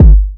Kicks
Kick 2 [ metro ].wav